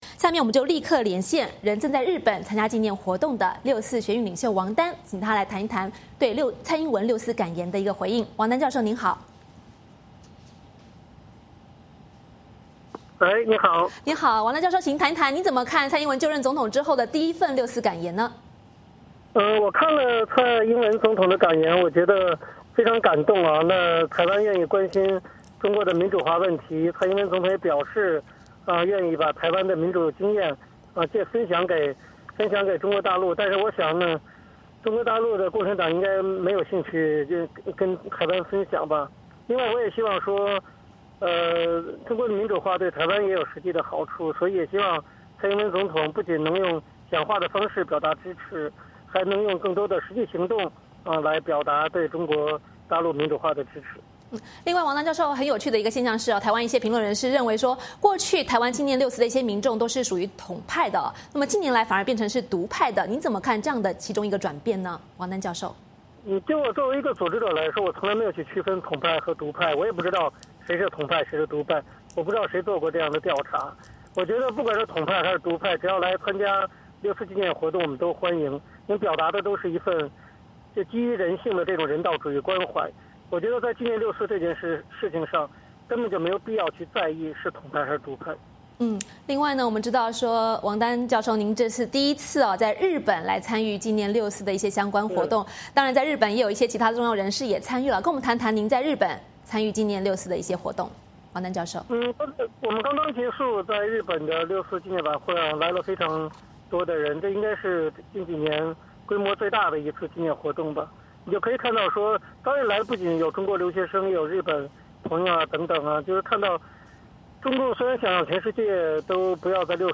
前六四学运领袖王丹在接受海峡论谈节目专访时表示，“蔡英文的六四感言非常令人感动，台湾人民对于六四的关心令人动容，关注大陆的民主化对于台湾有很多好处，也希望蔡英文和台湾不要把对六四的纪念仅停留在口头。”